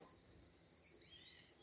Sooty-fronted Spinetail (Synallaxis frontalis)
Life Stage: Adult
Location or protected area: Luján
Condition: Wild
Certainty: Recorded vocal
Pijui-frente-gris.mp3